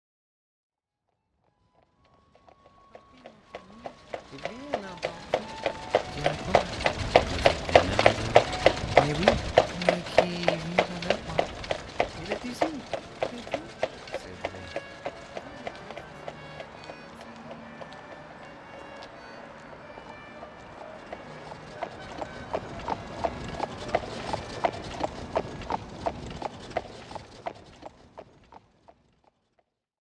Sound Design
• Paris Ambience (excerpt) - I recorded the horse and buggy sounds during a downtown carriage ride, street ambience while strolling down Beale Street, and dubbed the conversation and concertina in the studio.
Therefore mp3 recordings are not at full bandwidth and have suffered a slight degradation in sound quality from the original mixes.
ParisAmb_Sample.mp3